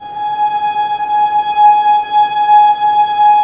Index of /90_sSampleCDs/Roland - String Master Series/STR_Vlns Bow FX/STR_Vls Sordino